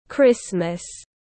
Christmas /ˈkrɪs.məs/